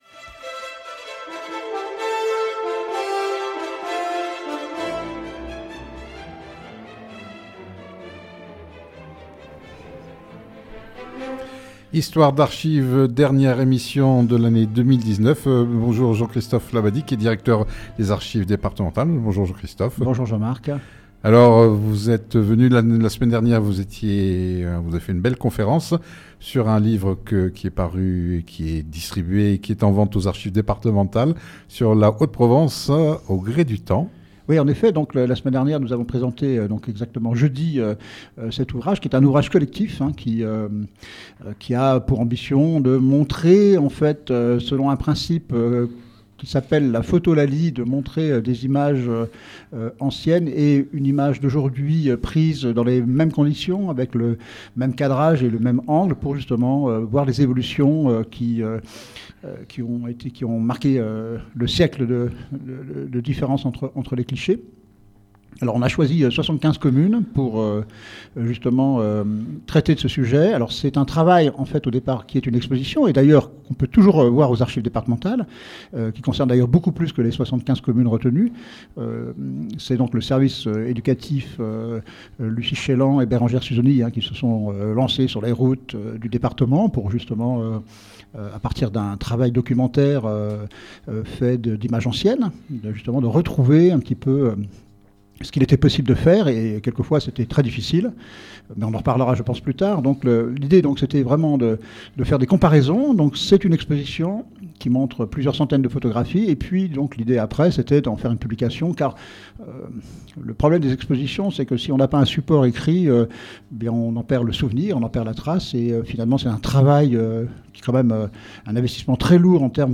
Une émission mensuelle chaque 3ème jeudi du mois de 9h15 à 10h, proposée, préparée et animée par